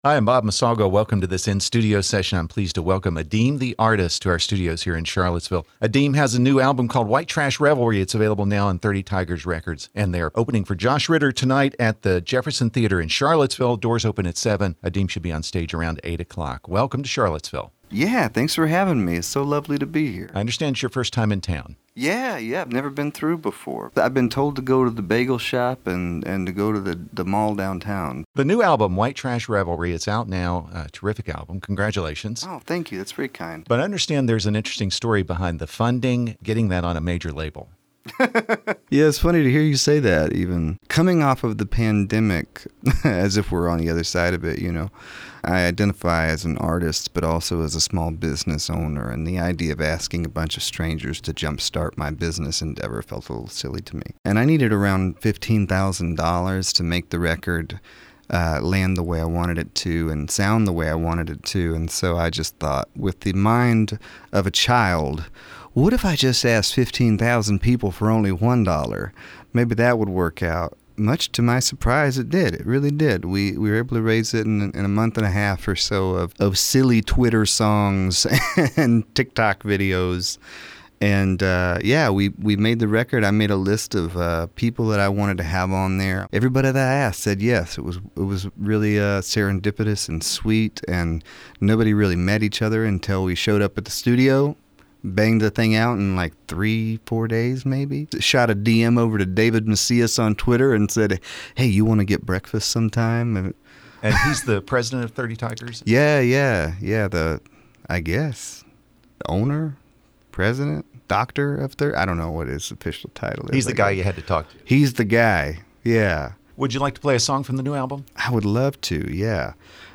In-Studio Session